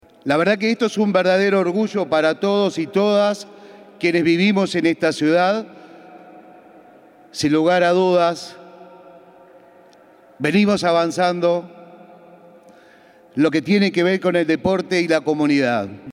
dario_pimienta_alcalde_de_canelones.mp3